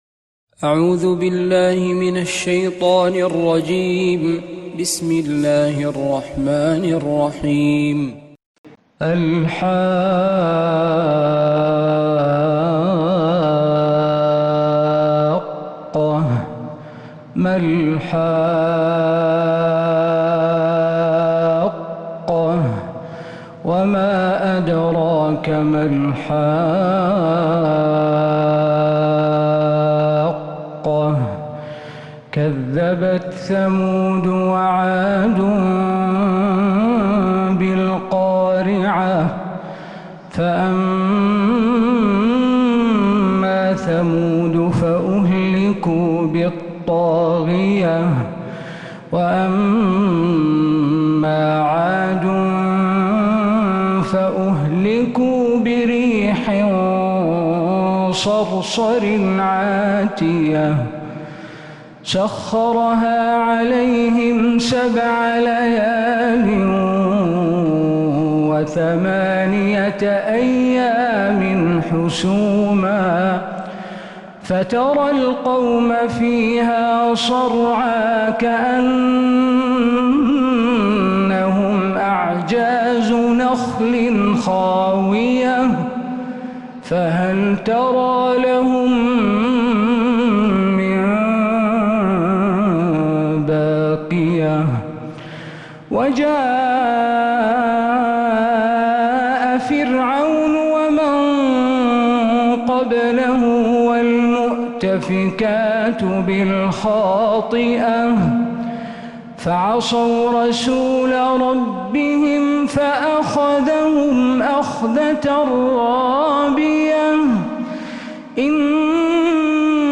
سورة الحاقة كاملة من فجريات الحرم النبوي